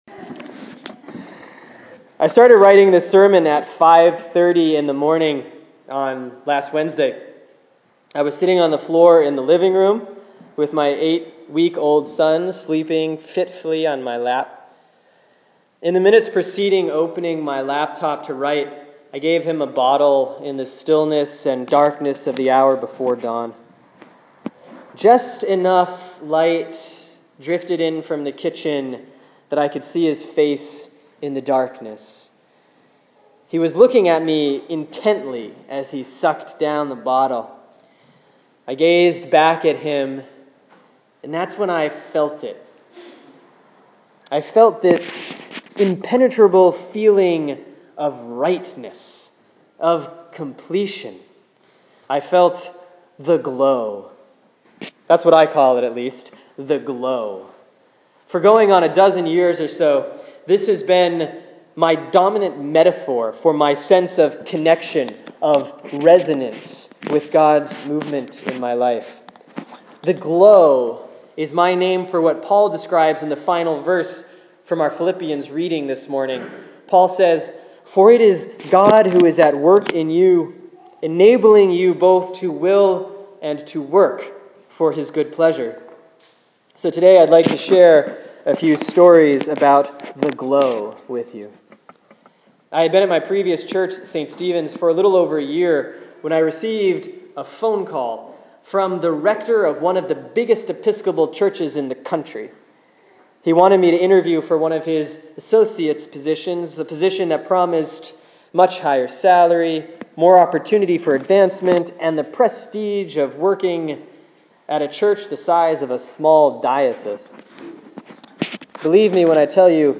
Sermon for Sunday, September 28, 2014 || Proper 21A || Philippians 2:1-13